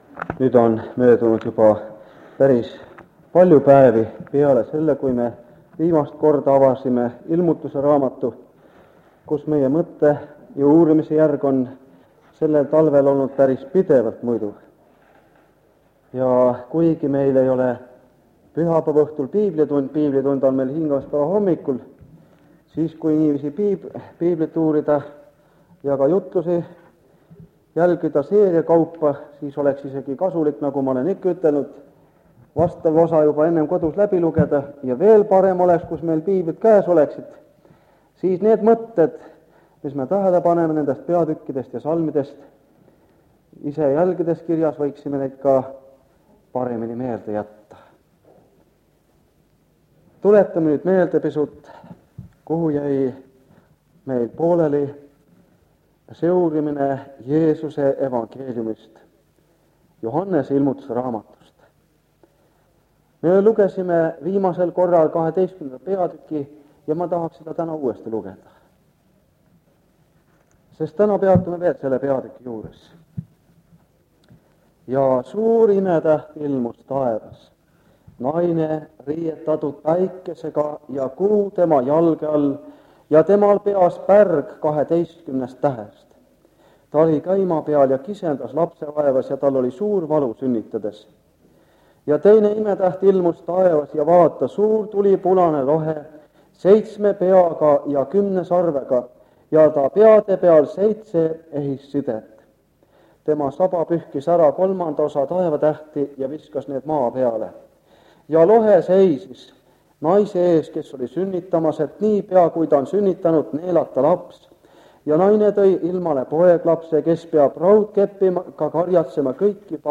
Ilmutuse raamatu seeriakoosolekud Kingissepa linna adventkoguduses